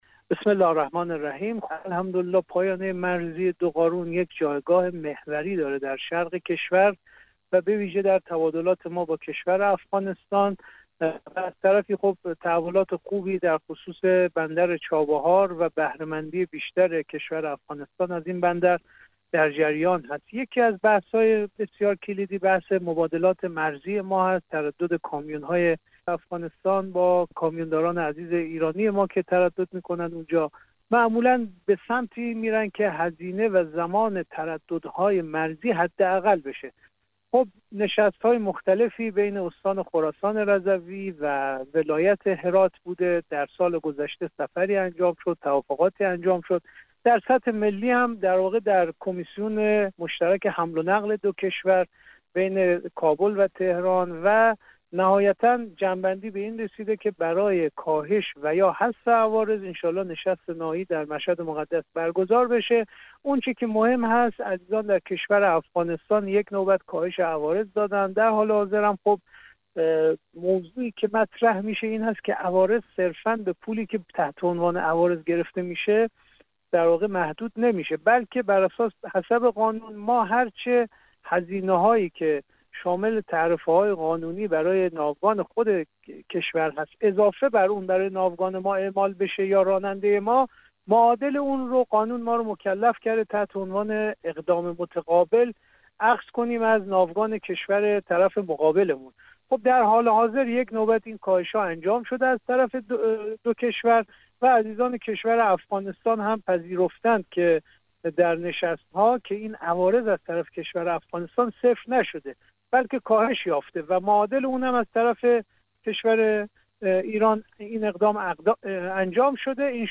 جعفر شهامت مدیرکل راهداری و حمل و نقل جاده ای استان خراسان رضوی ایران در مصاحبه با بخش خبر رادیو دری